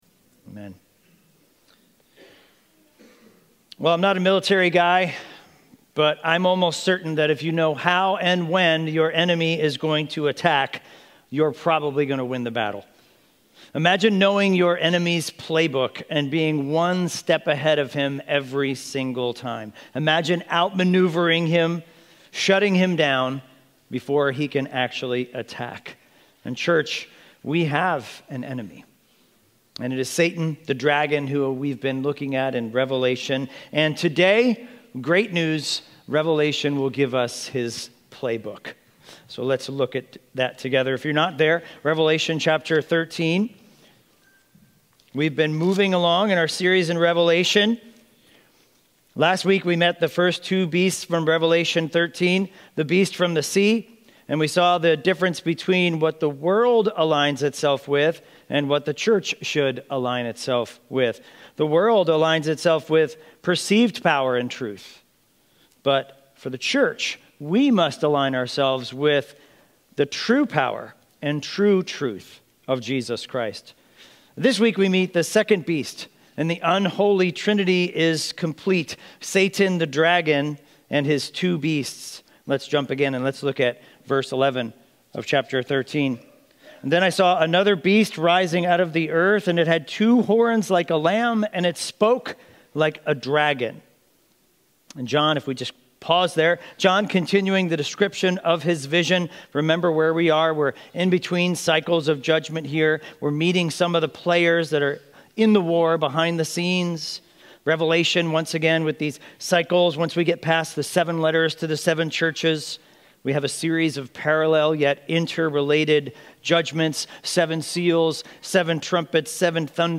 Highlands Bible Church Sermon Audio